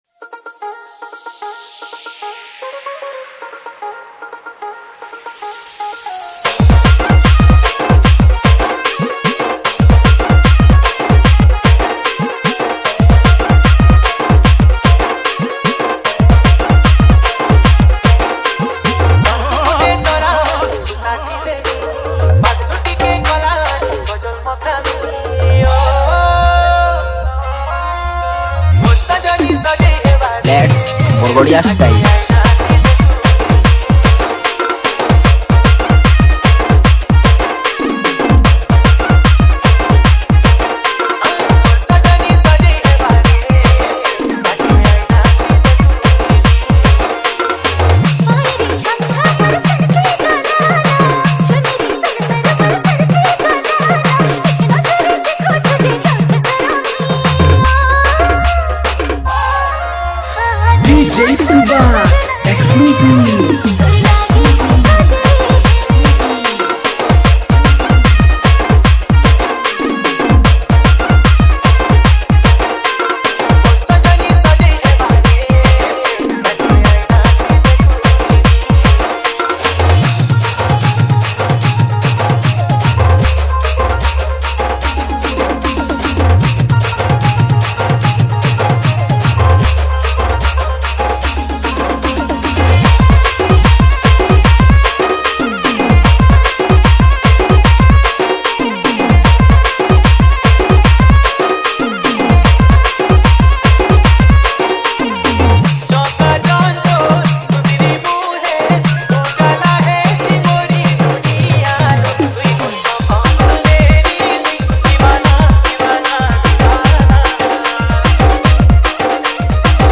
Category:  Sambalpuri Dj Song 2024